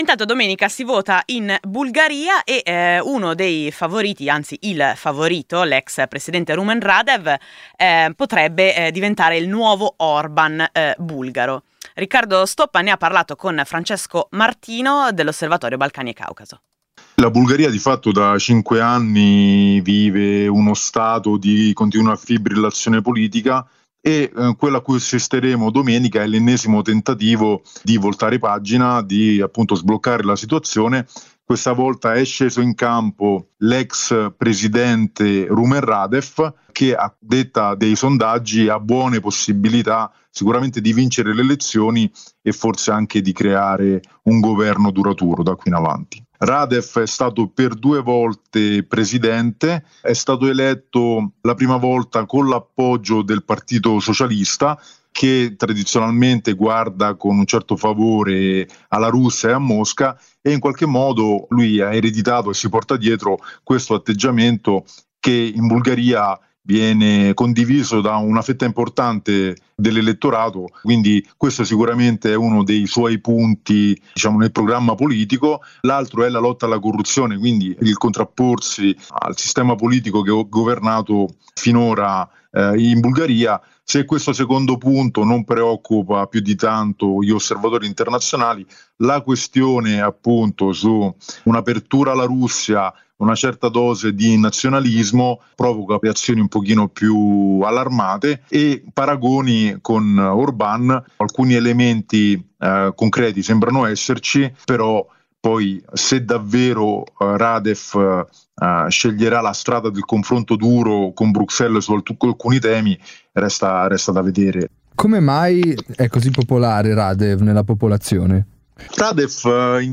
Alle elezioni previste in Bulgaria domenica 19 aprile, Rumen Radev, ex presidente e fondatore della nuova formazione politica “Bulgaria Progressista” viene dato come il futuro timoniere del paese, bloccato da un lungo stallo politico. Il commento
da Sofia alla trasmissione “Esteri” (17 aprile 2026)